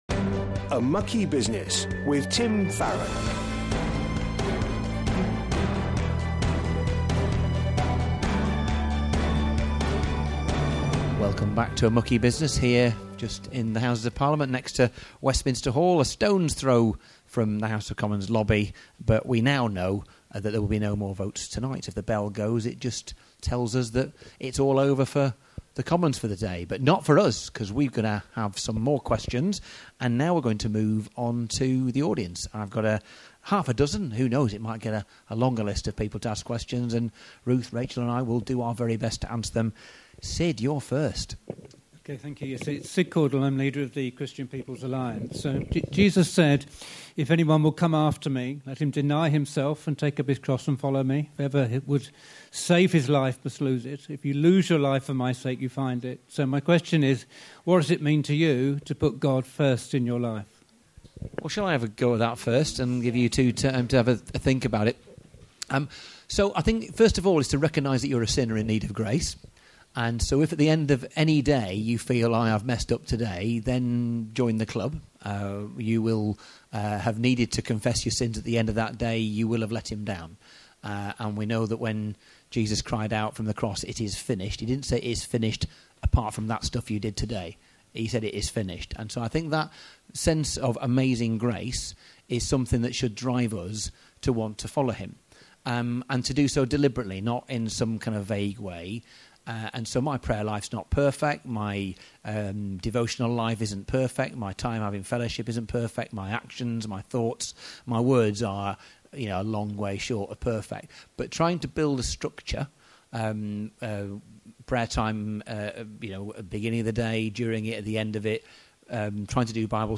This week, Tim returns with part two of a special episode of A Mucky Business recorded live from Parliament.